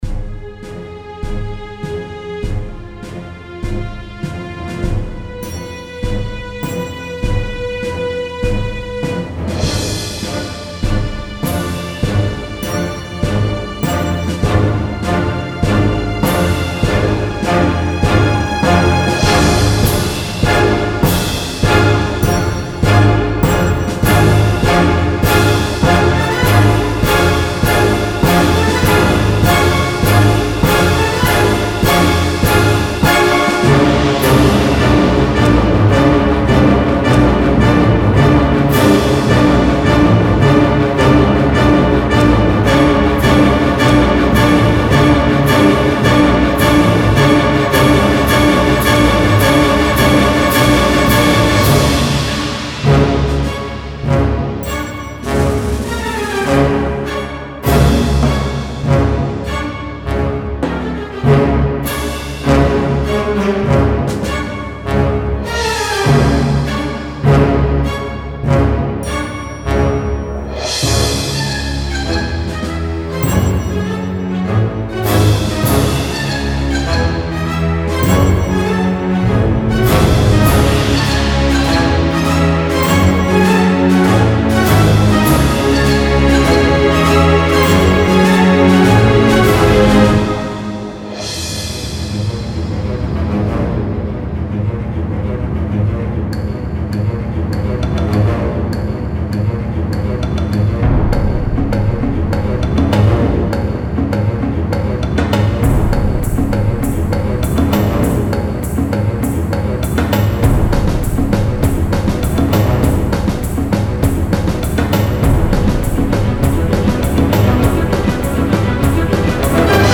Weird dramatic orchestral track.